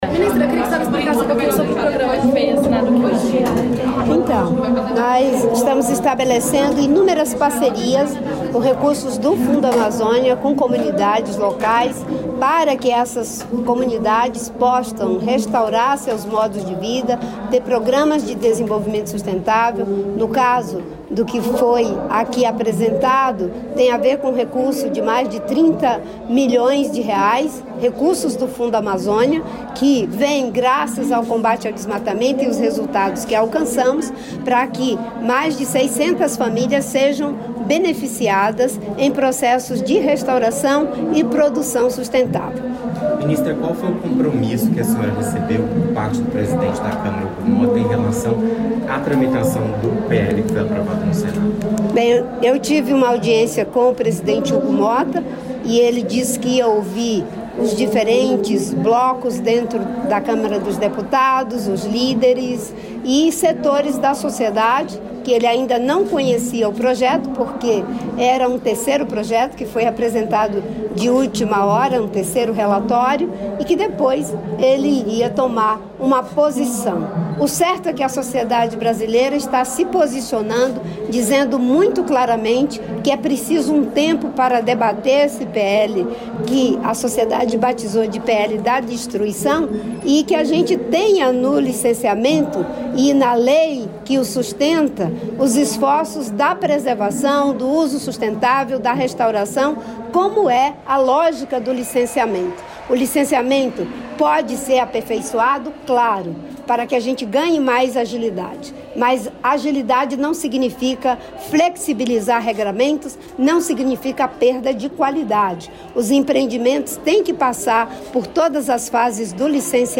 Íntegra dos discursos dos ministros, Renan Filho (Transportes) e Fernando Haddad (Fazenda), em visita às obras da Rodovia Presidente Dutra, na Serra das Araras-RJ, nesta terça-feira (15), em Paracambi, no Rio de Janeiro.